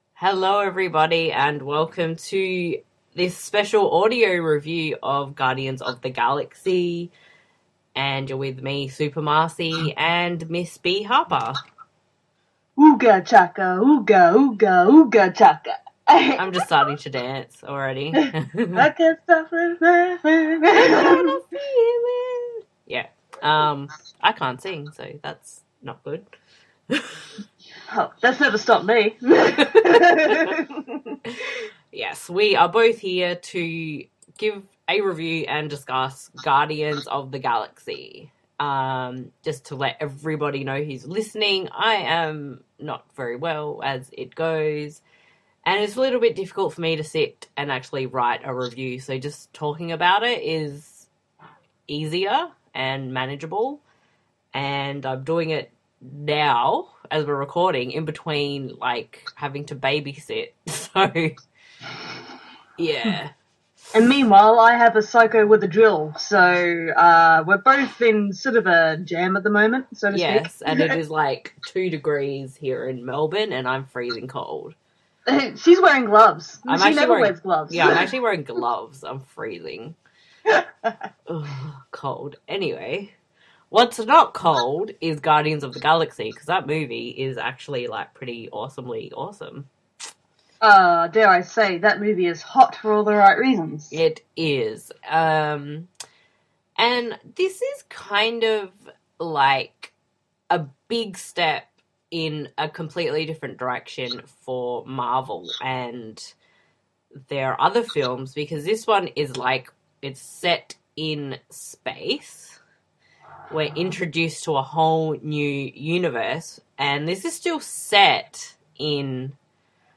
[Audio Review] Guardians Of The Galaxy
guardians-of-galaxy-review.mp3